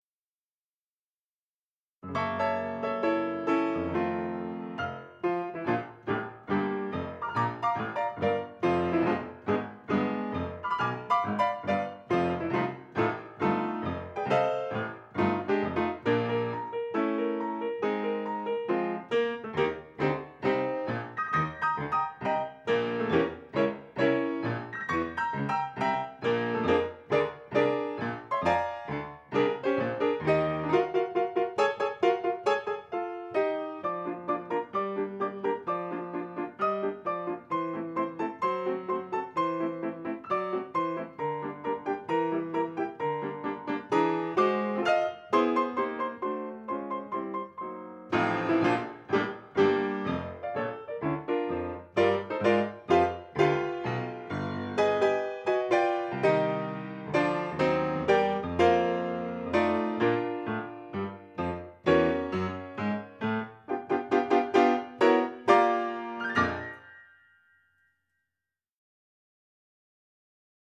Complete piano music